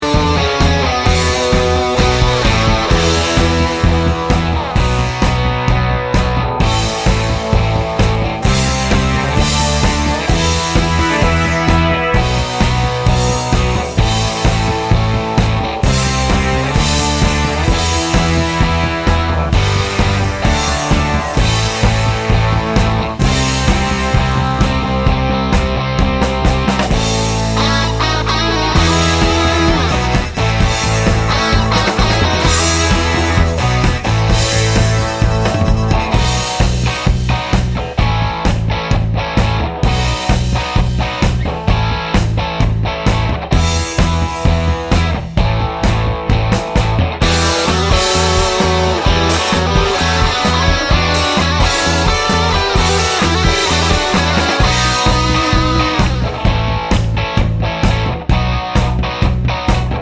no Backing Vocals Glam Rock 3:30 Buy £1.50